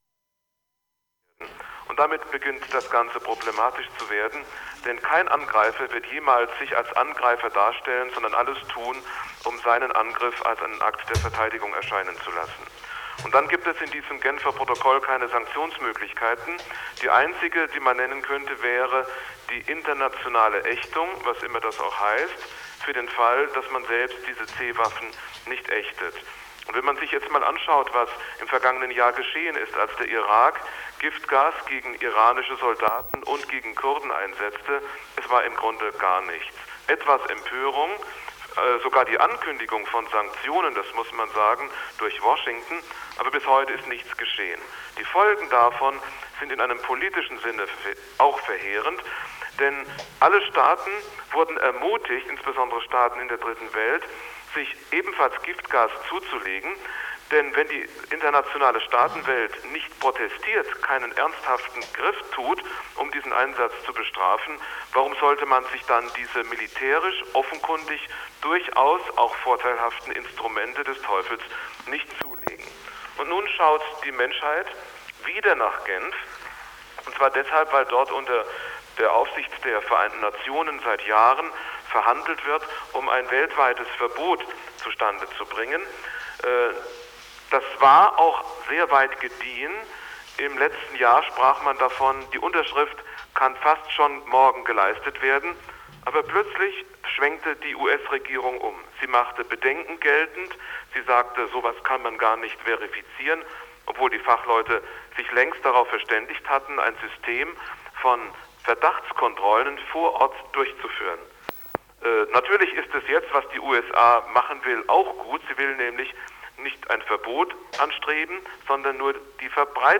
10.01.89 3 Gastkommentar zu Chemiewaffen-Konferenz
2.Gastkommentar zu Chemiewaffen Konferenz.mp3